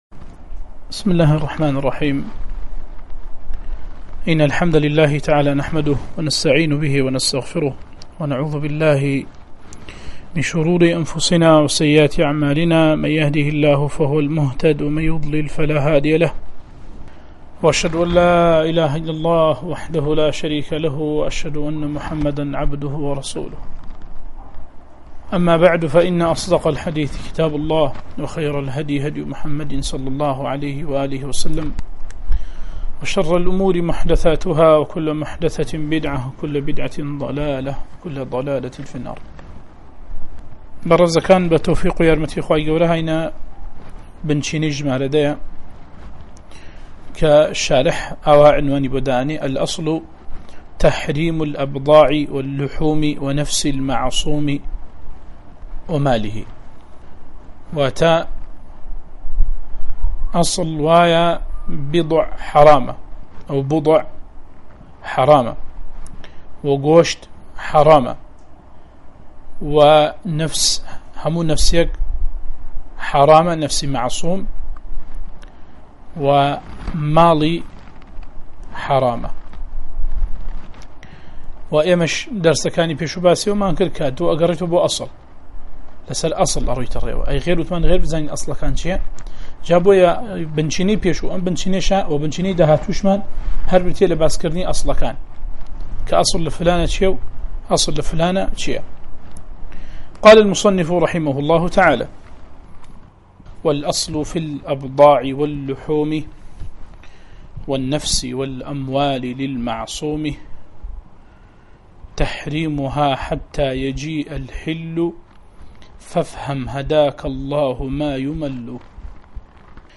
وانەی 16 - ڕاڤه‌ی القواعد الفقهية لابن سعدي